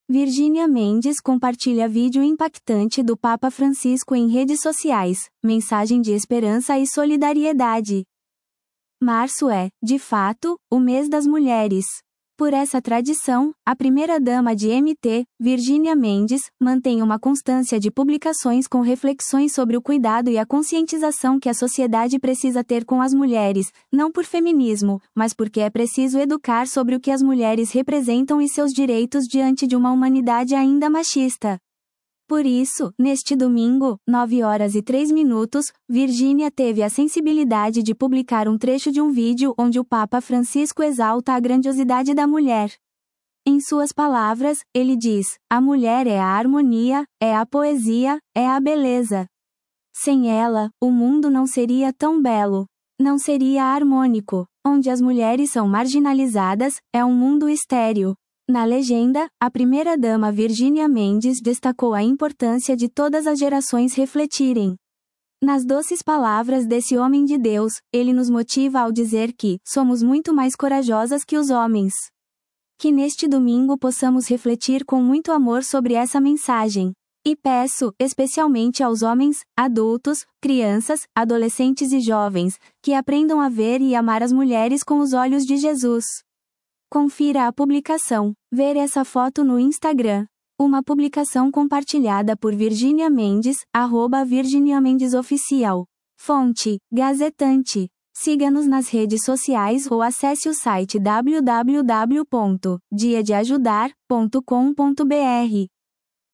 um vídeo onde o Papa Francisco exalta a grandiosidade da mulher. Em suas palavras, ele diz: “A mulher é a harmonia, é a poesia, é a beleza.